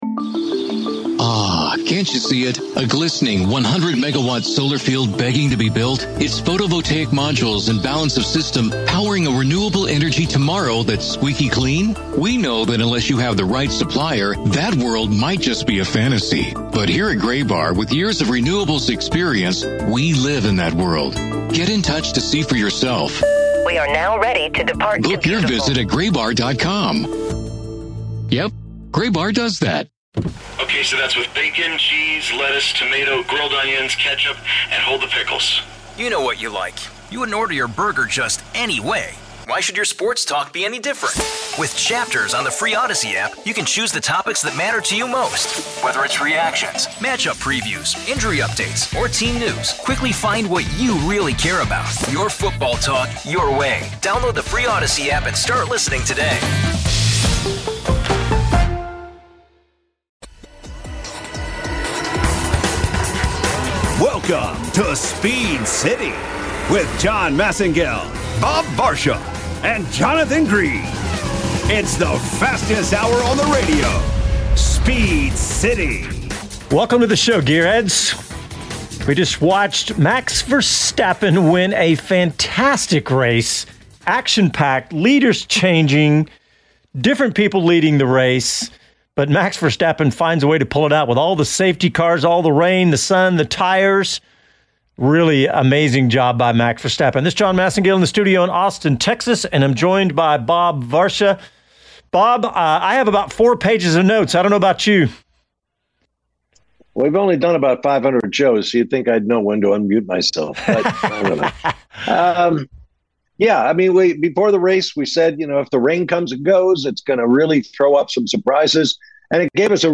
live from the paddock at Circuit Gilles Villeneuve.